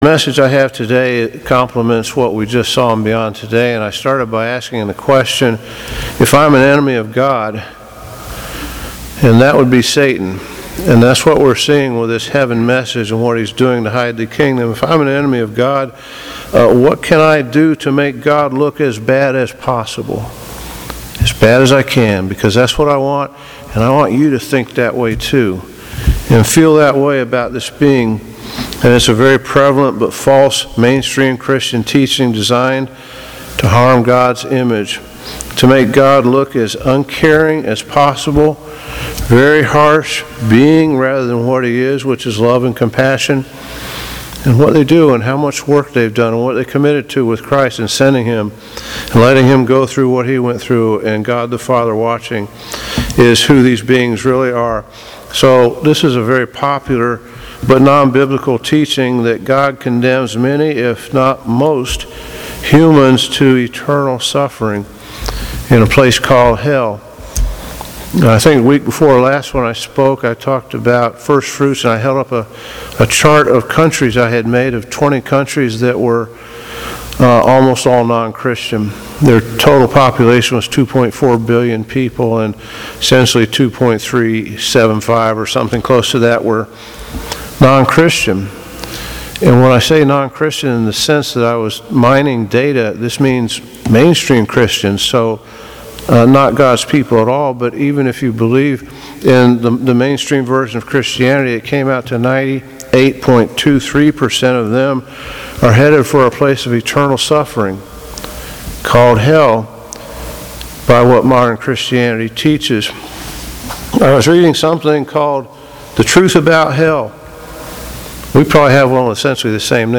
Sermons
Given in Buford, GA